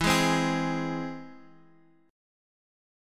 E+ chord